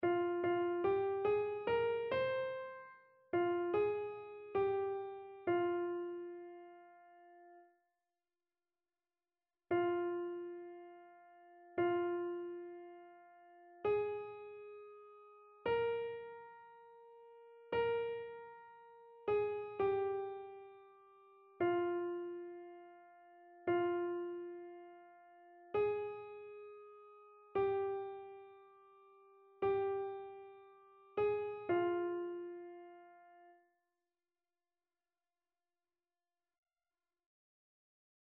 Chœur